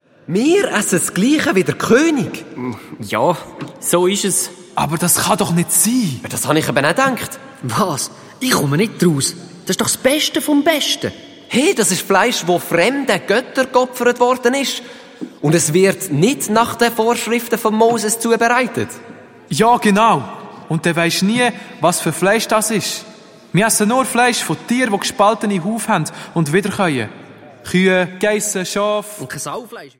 Zusätzlich enthalten: Mehrere Songs, das Hörspiel „D‘Geburiparty“ der lustigen Bärenkinder der Adonia-KidsParty und farbige Bilder der Geschichten im CD-Booklet!
Hörspiel-CD mit Download-Code